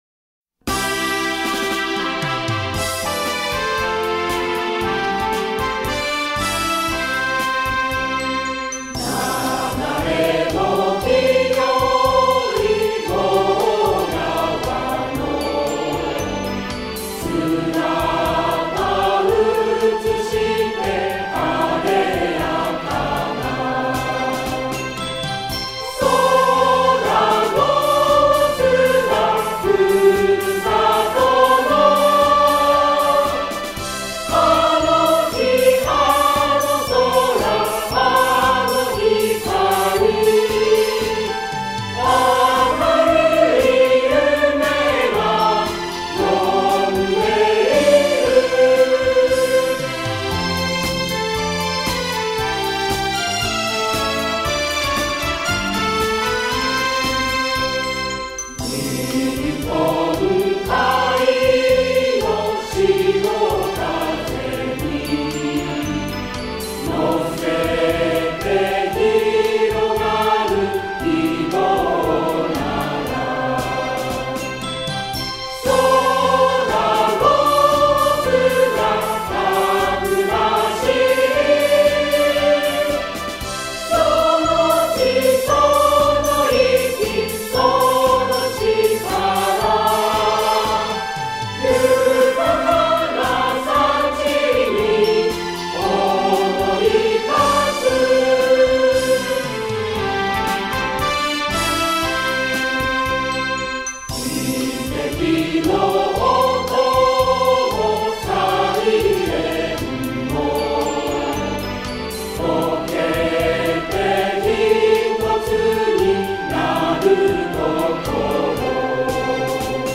江津市歌　音源（オケ入り）【歌：江津市民混声合唱団】 [その他のファイル：2.41MB]